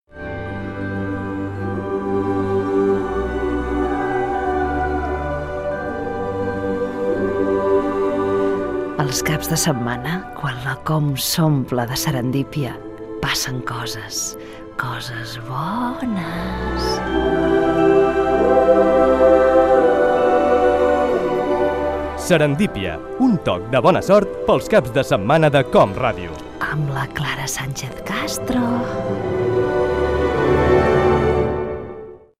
Promoció del programa
Fragment extret de l'arxiu sonor de COM Ràdio